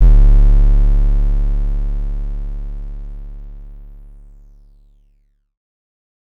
kits/OZ/808s/OZ-808 (909).wav at ts